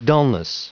Prononciation du mot dulness en anglais (fichier audio)
Prononciation du mot : dulness